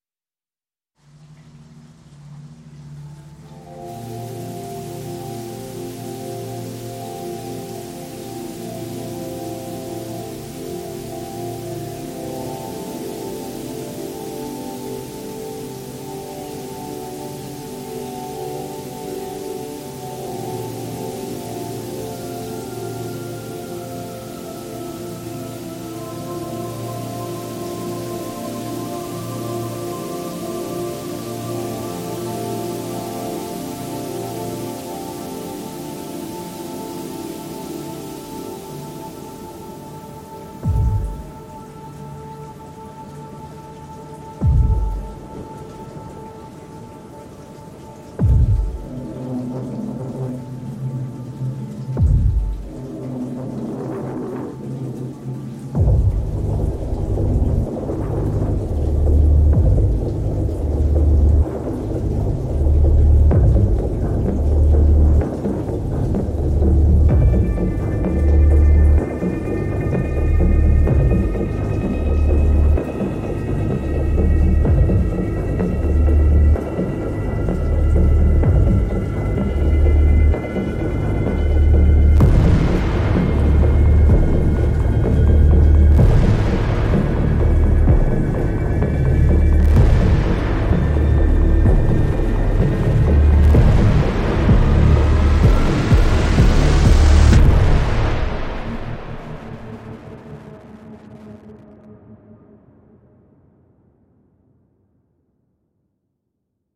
-小雨，中雨和大雨有多种变化
-远处的雨和风具有多种变化
单个补丁可以使小雨慢慢转变成咆哮的风暴。